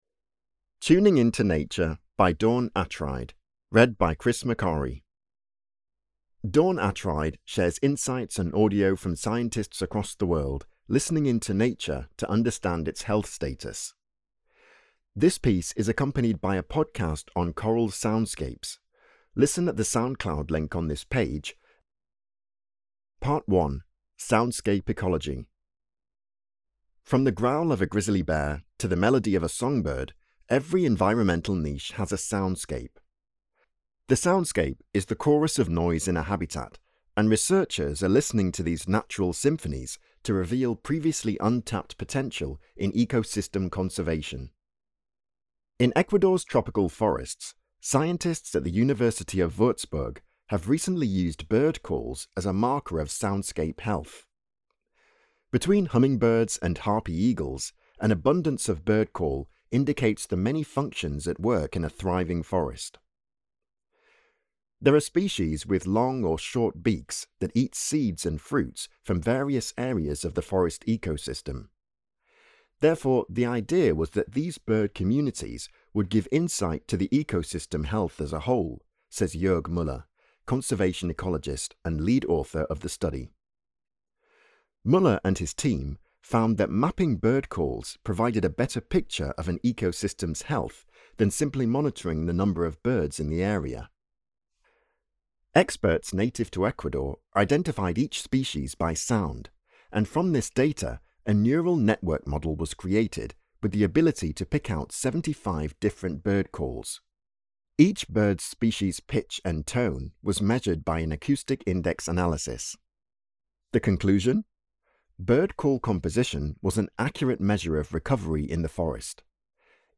Container Magazine · Tuning Into Nature: Coral Soundscape Soundscape Ecology From the growl of a grizzly bear to the melody of a songbird, every environmental niche has a soundscape. The soundscape is the chorus of noise in a habitat and researchers are listening to these natural symphonies to reveal previously untapped potential in ecosystem conservation.